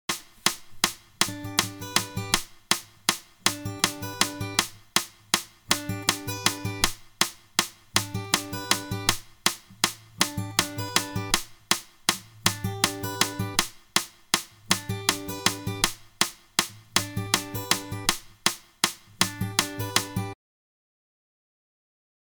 Full Speed mp3
Repeats 8X